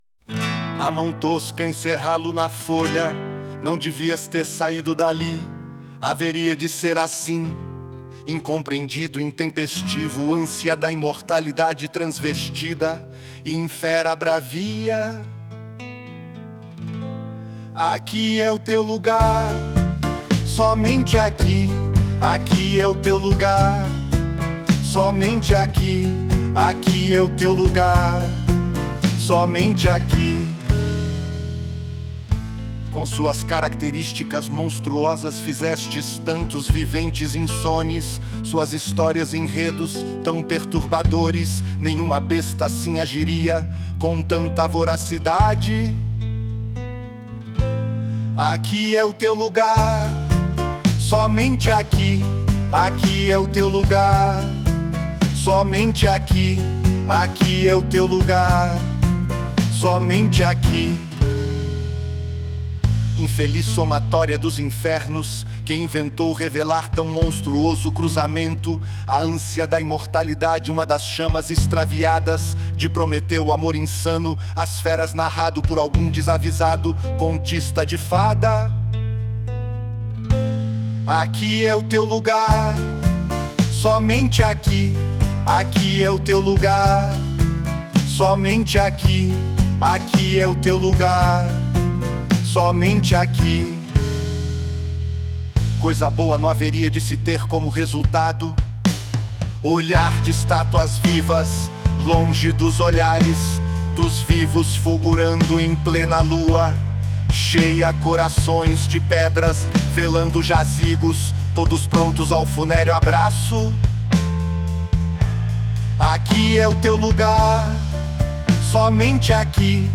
[Vocal Masculino]